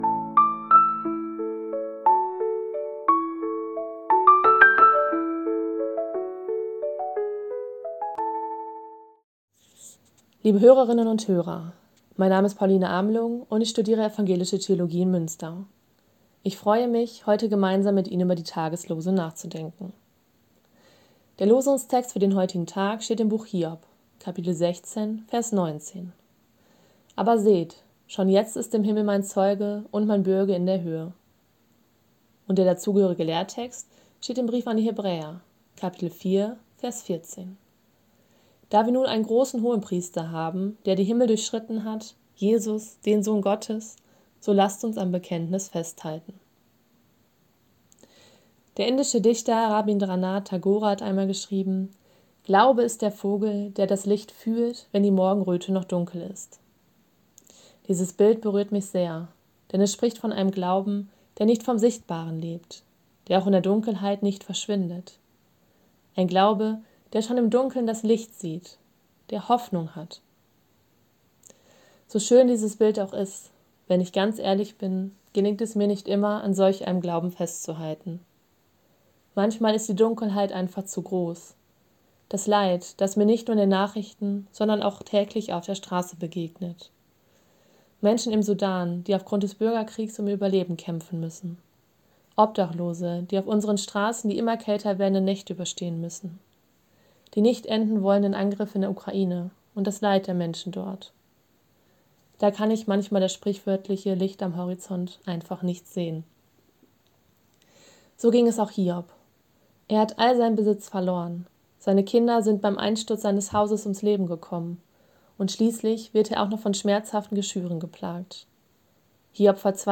Losungsandachten
Text und Sprecherin